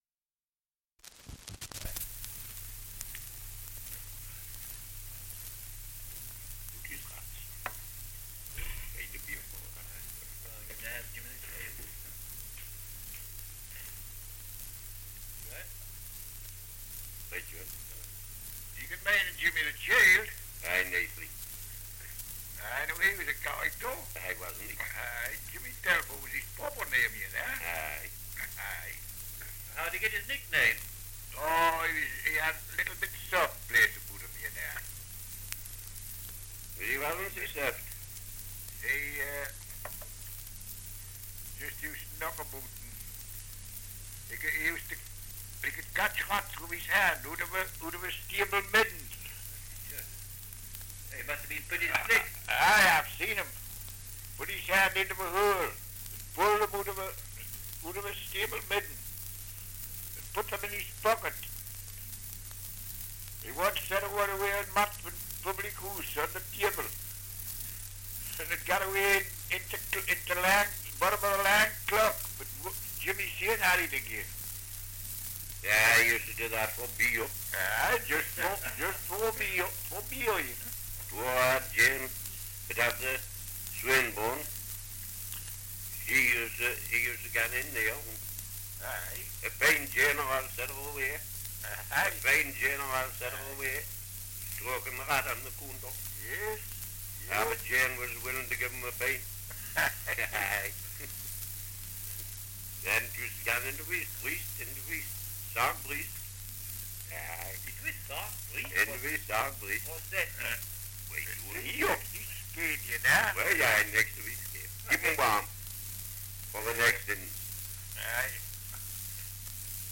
1 - Dialect recording in Stamfordham, Northumberland
78 r.p.m., cellulose nitrate on aluminium